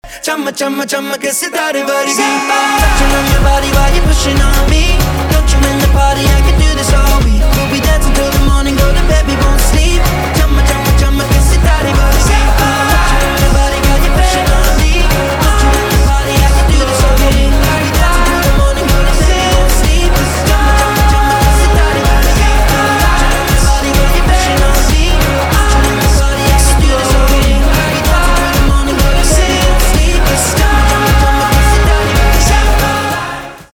поп
танцевальные , позитивные